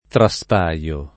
trasparire [traSpar&re] (ant. trasparere [traSpar%re]) v.; trasparisco [traSpar&Sko], ‑sci (meno com. traspaio [